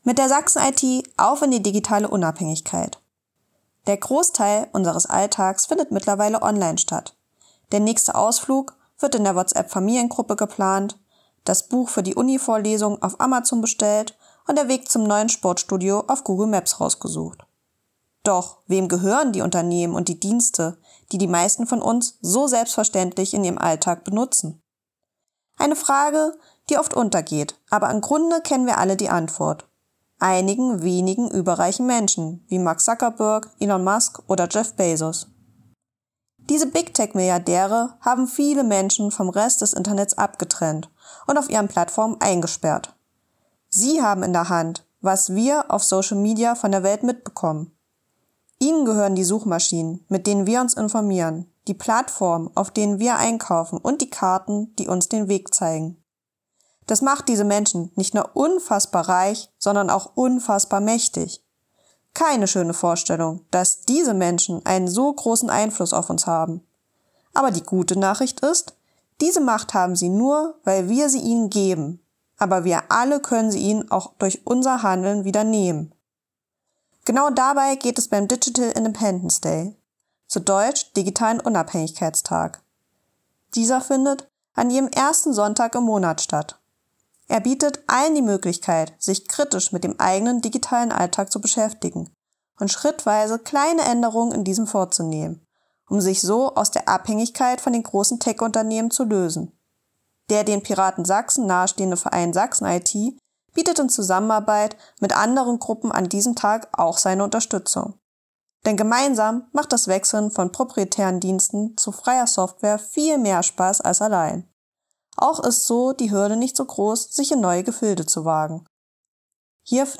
Text als Hörfassung: ▶